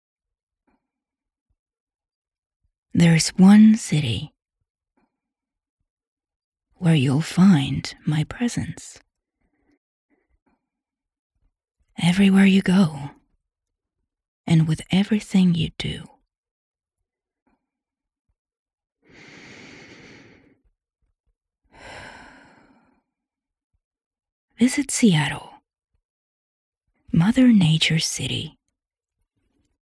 Documentales
Soy locutora estonia nativa y trabajo tanto en estonio como en inglés, ¡con un ligero acento!
Mi acento es lo suficientemente suave como para ser fácilmente comprensible, además de ser adecuado para conectar con gente de todo el mundo.